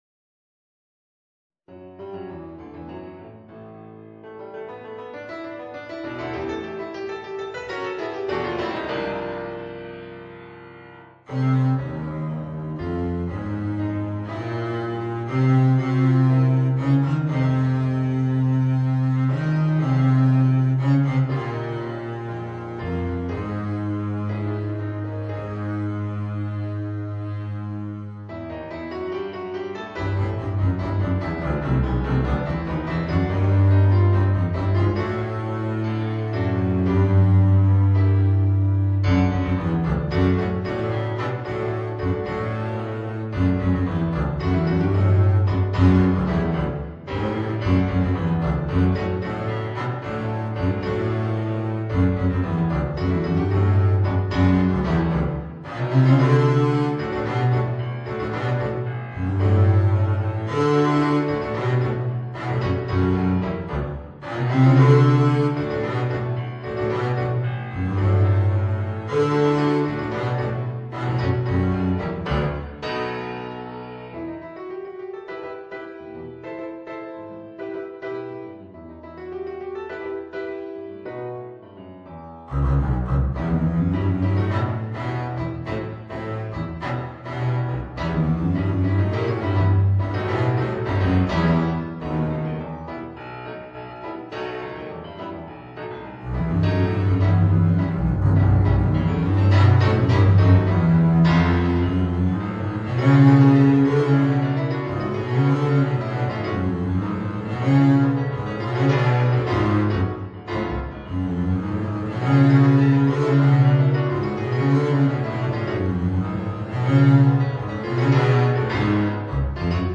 Voicing: Contrabass and Piano